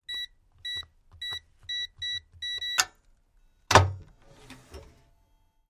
Звуки сейфа: установка кода на дверь сейфа